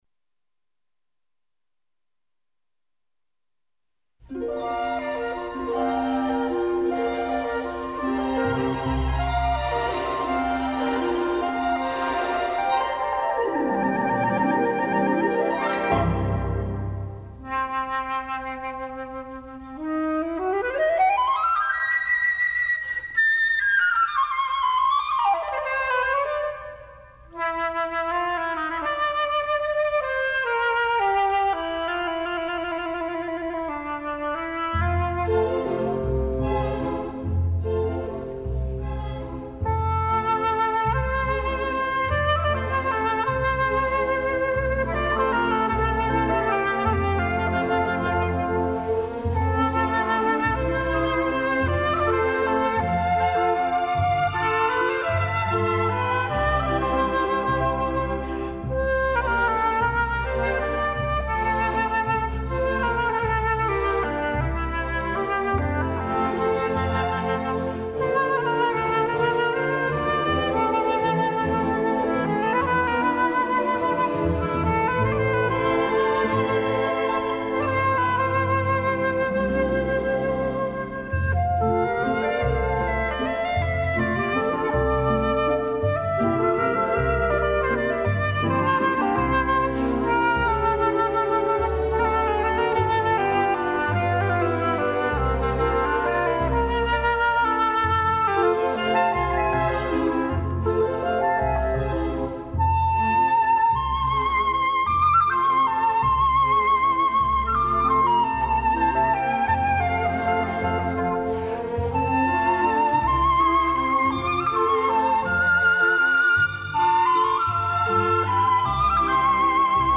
[2005-10-16]一首长笛夜曲